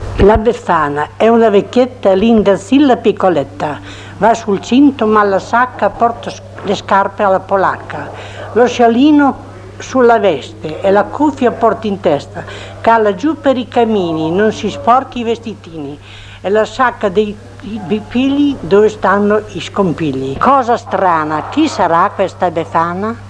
filastrocca - la befana.mp3